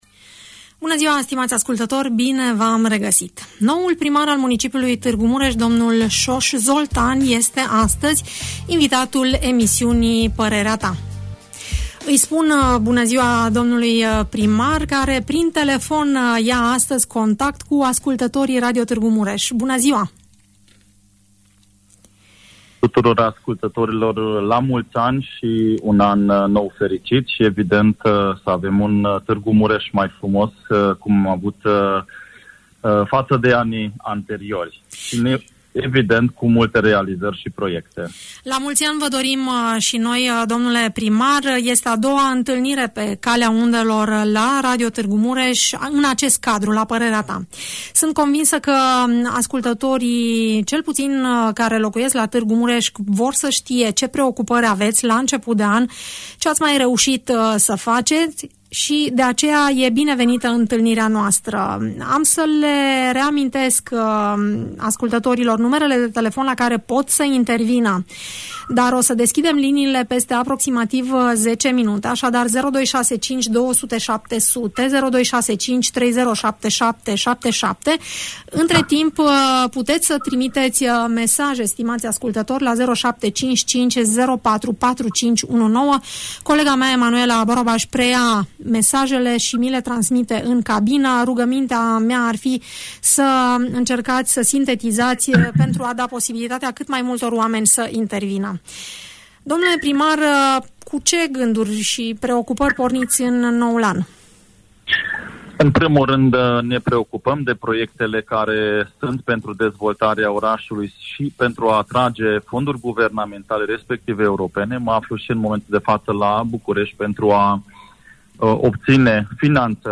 Primarul Tg. Mureșului, în direct la Radio! - Radio Romania Targu Mures
Soós Zoltán, noul primar al municipiului Tg. Mureș, are câteva provocări majore la început de mandat: să reintroducă taxa de salubritate, să atragă finanțări pentru a finaliza drumurile/centurile ocolitoare ale orașului sau să găsească soluții la problemele lăsate nerezolvate de către vechea administrație. Invitat la Radio Tg. Mureș, acesta își prezintă planurile în fața ascultătorilor.